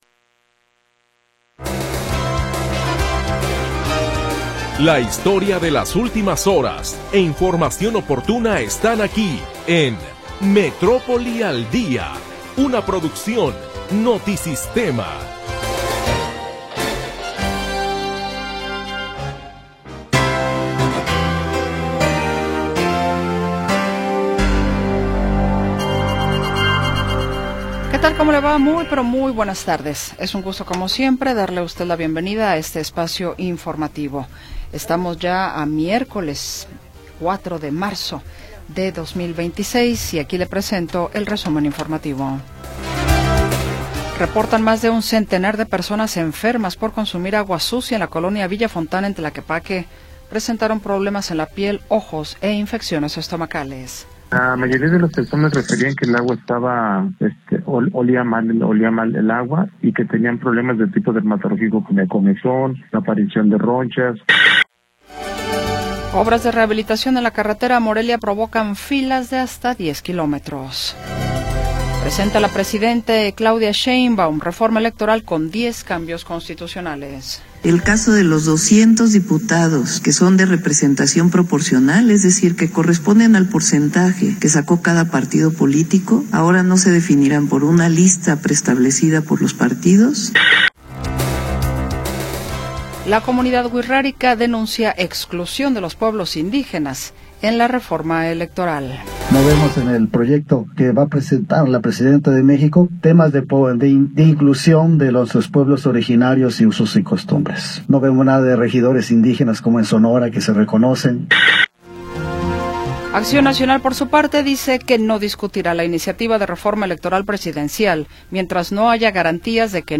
Primera hora del programa transmitido el 4 de Marzo de 2026.